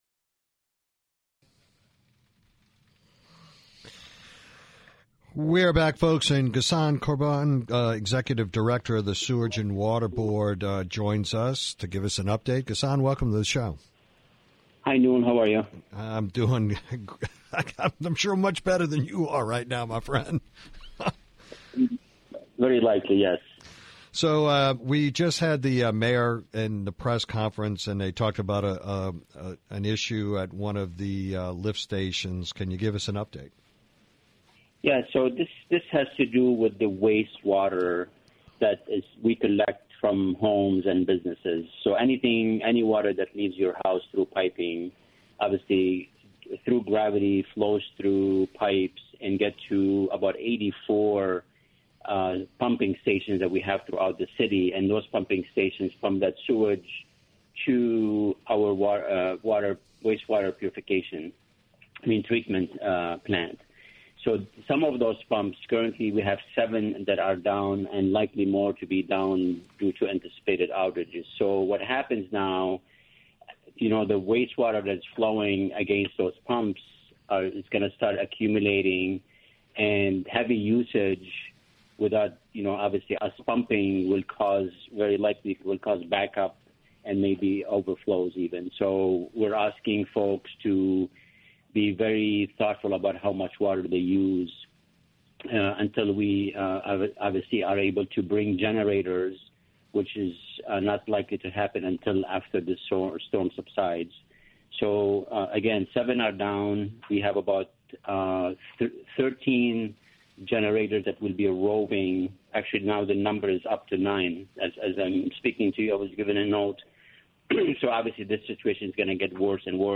this interview 2 went down while he was being interviewd